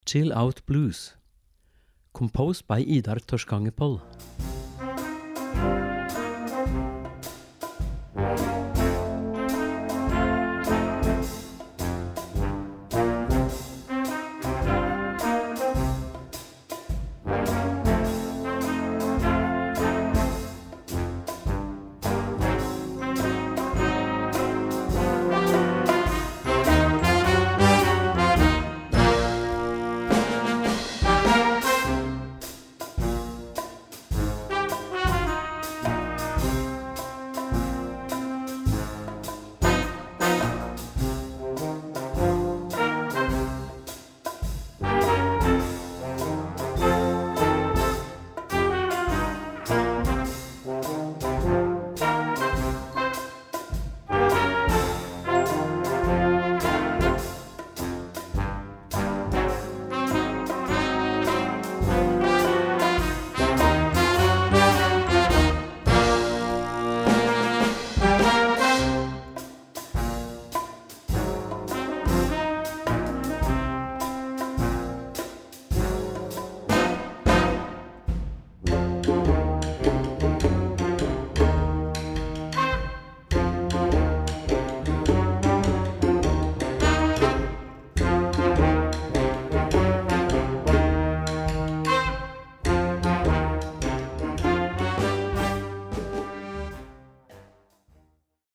für Jugendblasorchester
Besetzung: Blasorchester